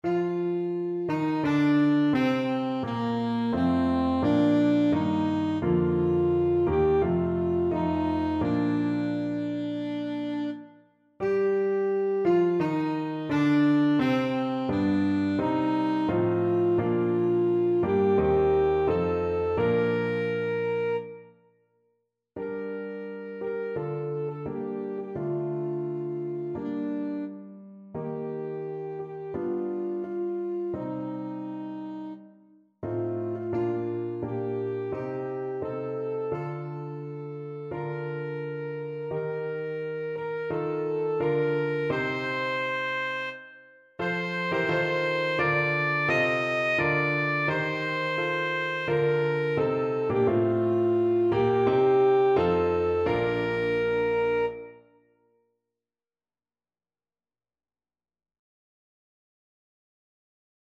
Alto Saxophone version
Alto Saxophone
4/4 (View more 4/4 Music)
Maestoso =86
Traditional (View more Traditional Saxophone Music)